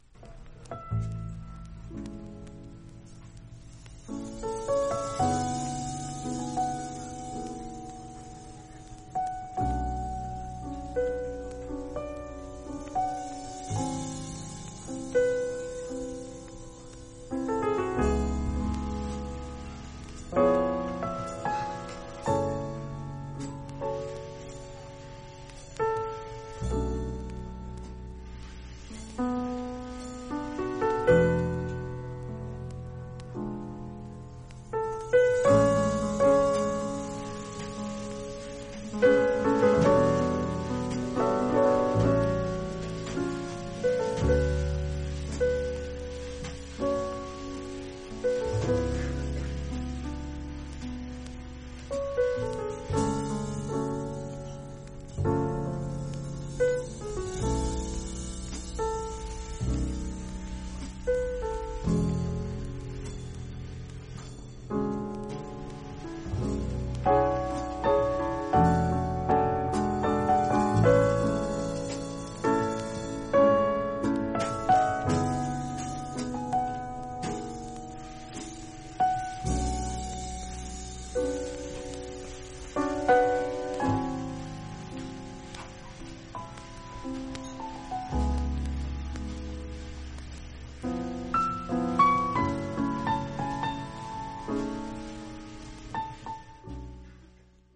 実際のレコードからのサンプル↓